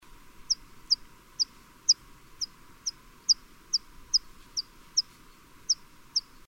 Vuorikirvinen / Water Pipit (Anthus spinoletta)
(11.) Heinäkuu 2005 Kirgisia Varoitusääniä. / July 2005, Kyrgyzstan. Alarm calls.